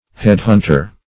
Head-hunter \Head"-hunt`er\ (h[e^]d"h[u^]nt`[~e]r), n.